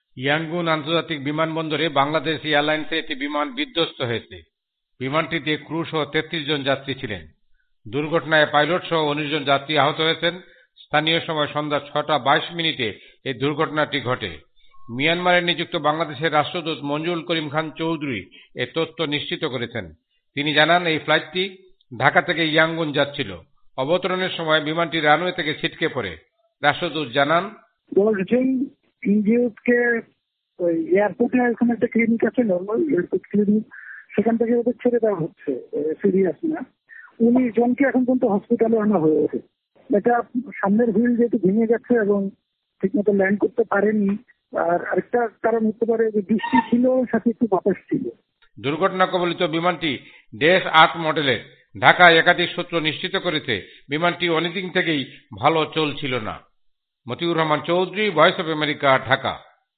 প্রতিবেদন